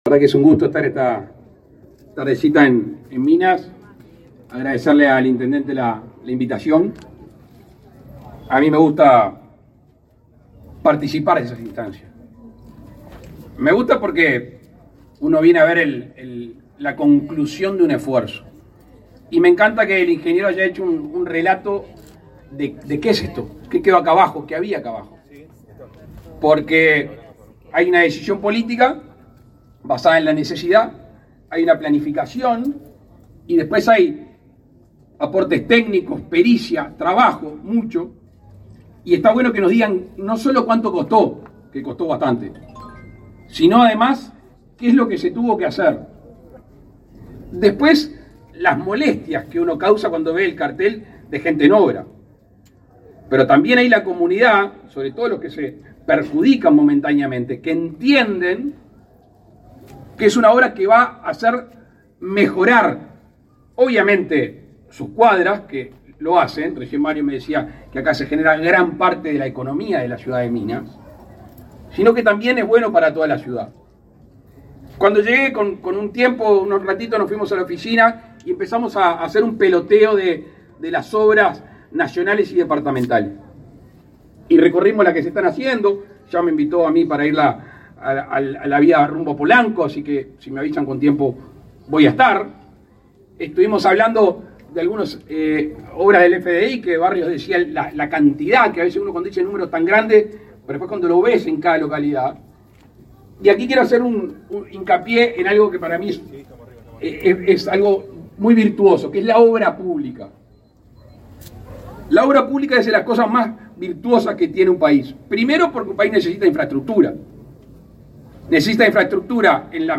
Palabras del presidente de la República, Luis Lacalle Pou
Con la participación del presidente de la República, Luis Lacalle Pou, fue inaugurado, este 21 de febrero, el Bulevar Luis Alberto de Herrera, en la